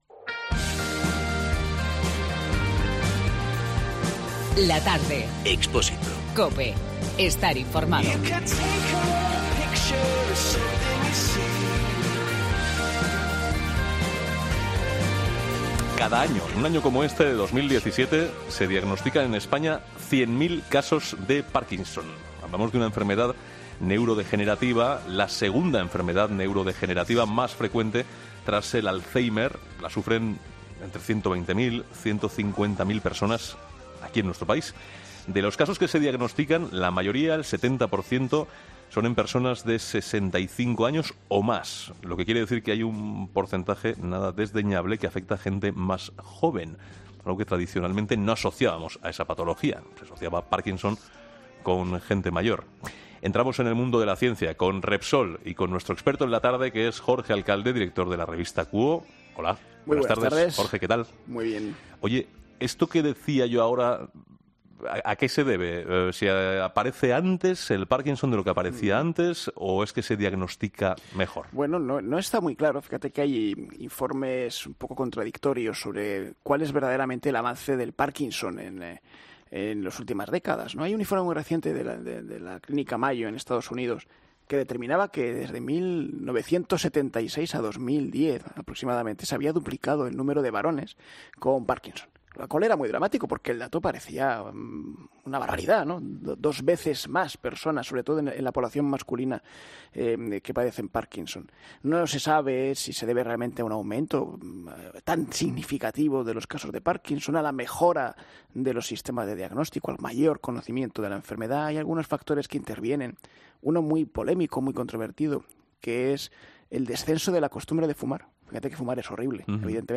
hablamos con este joven en el Día Mundial de este mal.